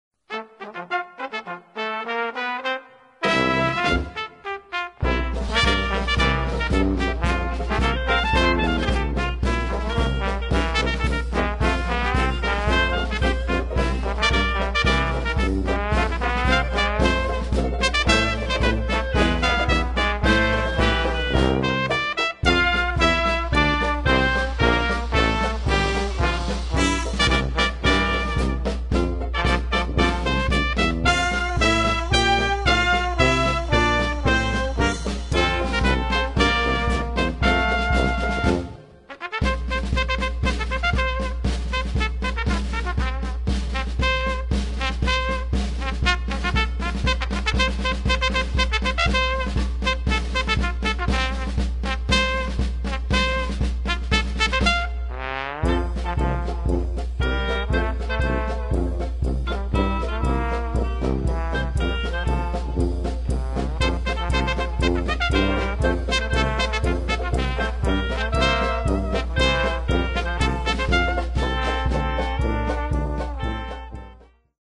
Tra le musiche sacre mutuate, un esempio è Oh Tannenbaüm, successivamente adattato a marcia e suonato in stile jazz con il titolo di